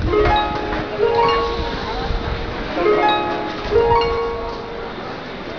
この曲なんですが、改めて聞いてみると、ちょっとしか似てない（笑）
old-shinjuku-chuo.wav